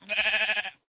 sheep2.ogg